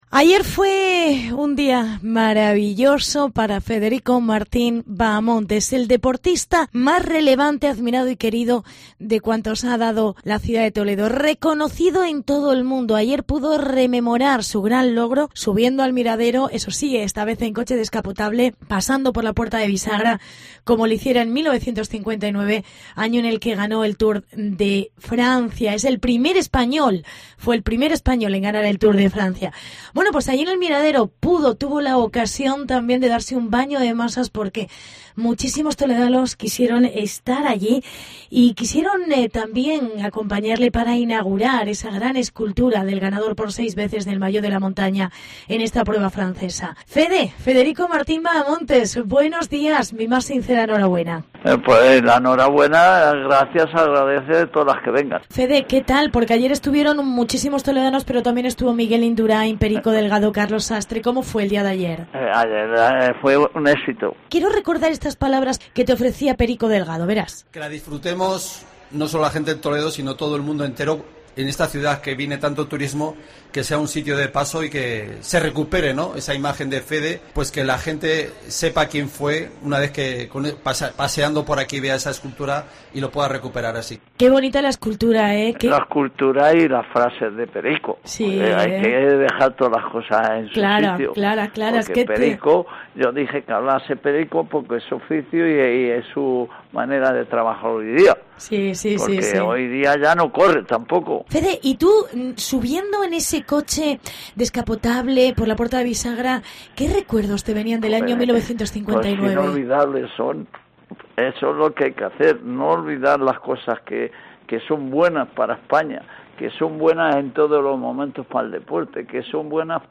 Entrevista con Federico Martín Bahamontes